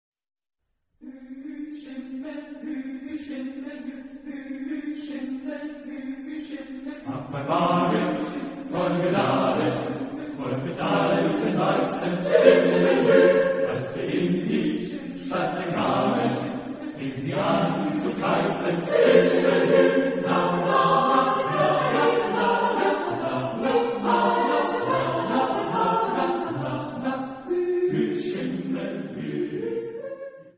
Genre-Style-Form: Secular ; Folk music ; Partsong
Type of Choir: SATB  (4 mixed voices )
Instruments: Tambourine
Tonality: F major